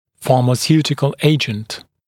[ˌfɑːmə’sjuːtɪkl ‘eɪʤənt][ˌфа:мэ’сйу:тикл ‘эйджэнт]фармацевтический препарат